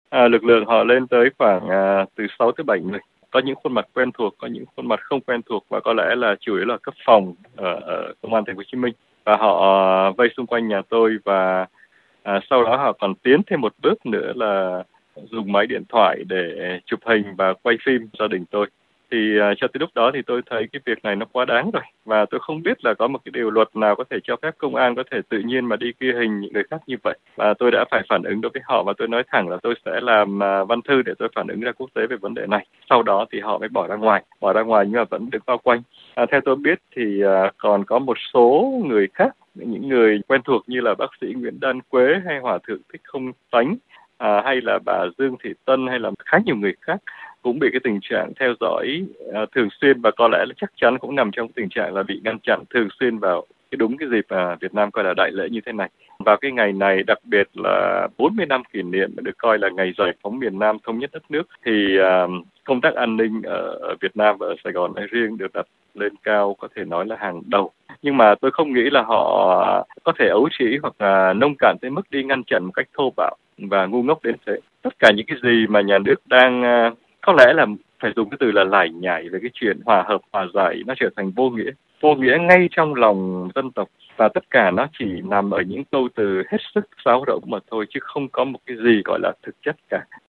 Trả lời RFI qua điện thoại